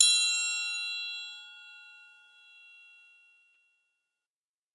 CINE Sounds » Triangle Ring Soft
描述：Soft ring of a triangle
标签： instrument triangle hit
声道立体声